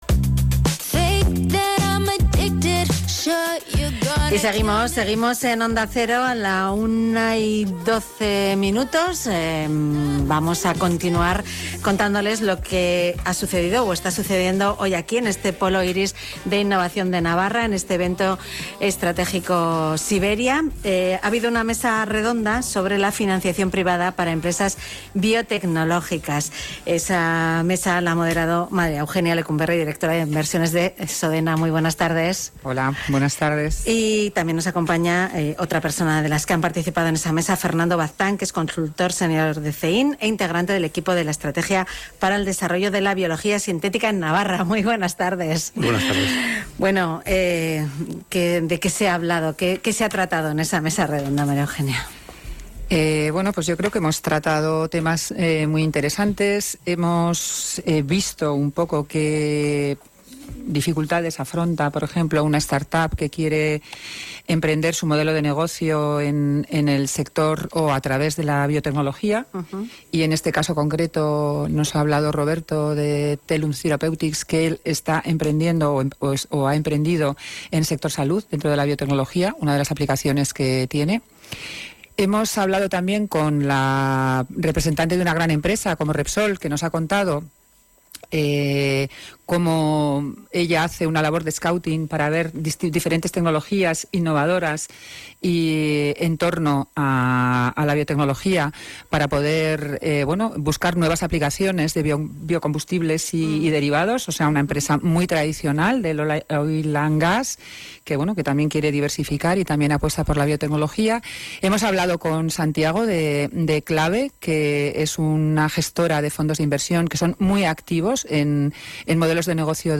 Onda Cero retransmitió su programa diario «Más de uno Pamplona»